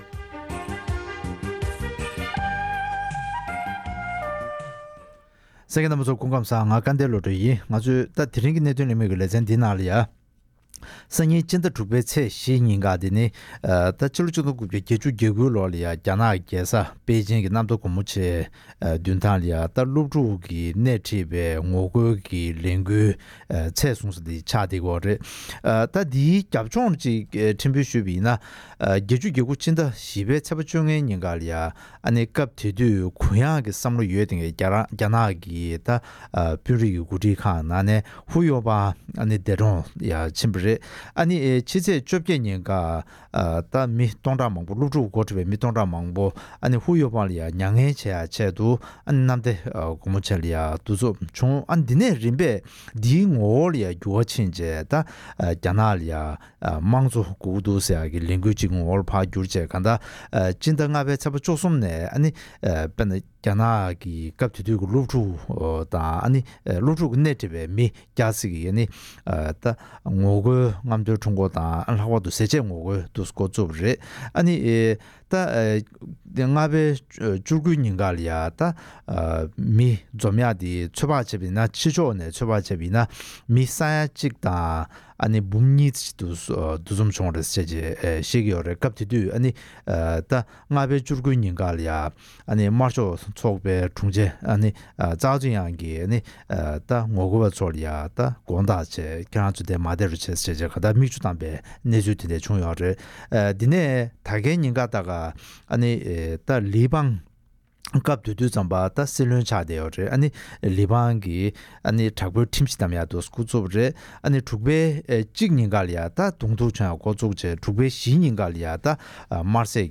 ༡༩༨༩ལོར་རྒྱ་ནག་ནང་བྱུང་བའི་དྲུག་བཞིའི་དོན་རྐྱེན་གྱི་རྒྱབ་ལྗོངས་དང་སྐབས་དེ་དུས་བོད་ནང་གི་ཆབ་སྲིད་གནས་སྟངས་སོགས་ཀྱི་ཐད་གླེང་མོལ།